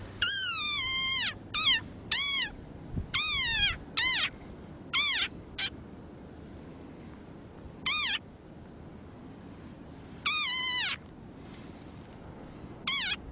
screaming-frog.mp3